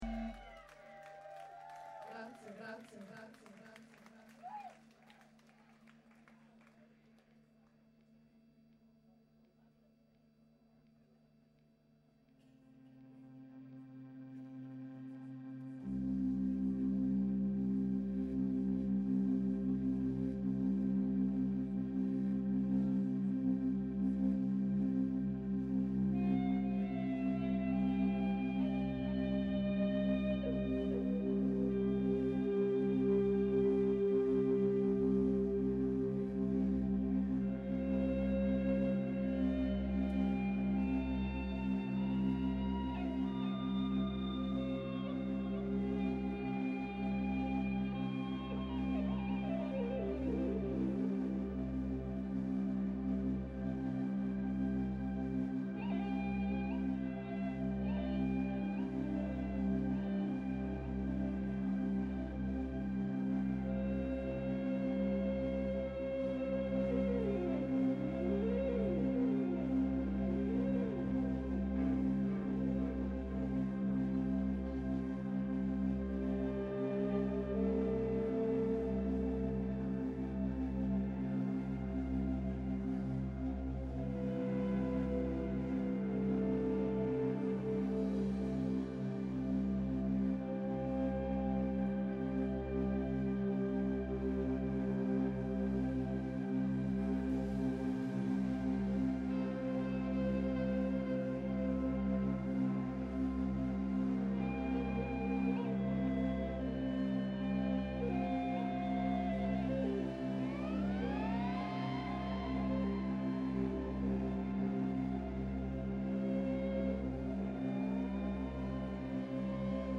Noise Rock, Post Punk e Blues